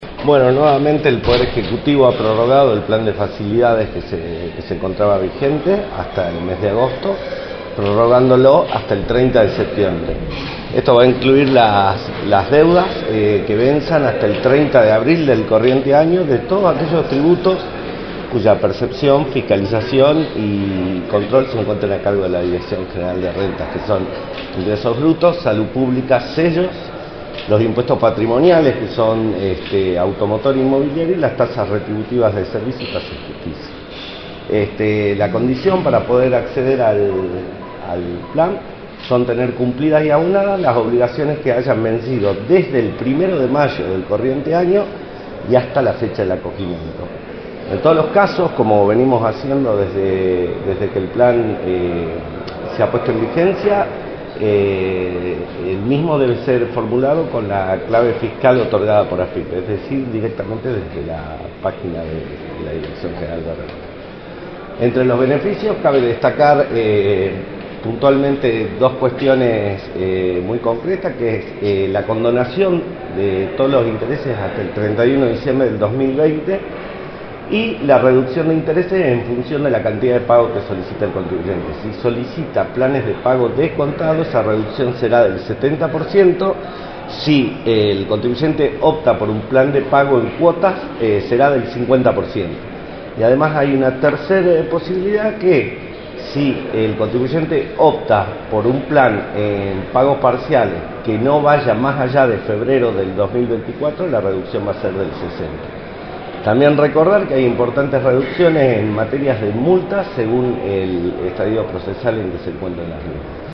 informó en Radio del Plata Tucumán, por la 93.9, los alcances y beneficios que tendrá la Prórroga de Plan de Facilidades de Pagos.